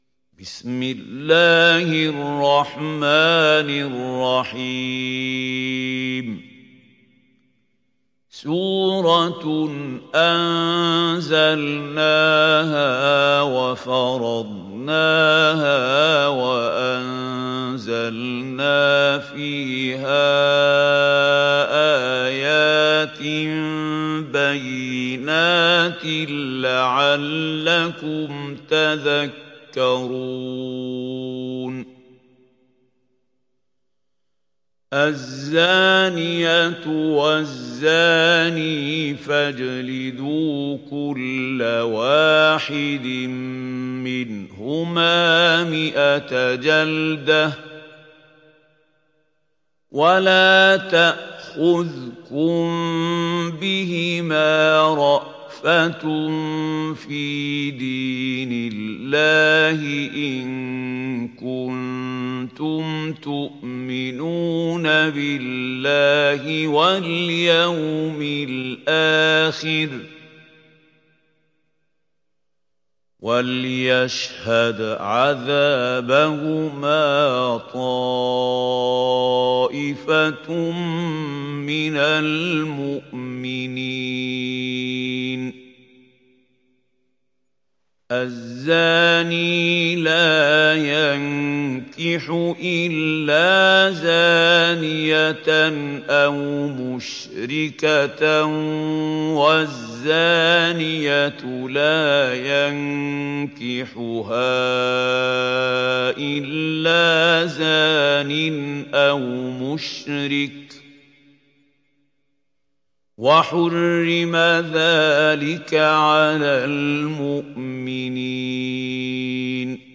دانلود سوره النور mp3 محمود خليل الحصري روایت حفص از عاصم, قرآن را دانلود کنید و گوش کن mp3 ، لینک مستقیم کامل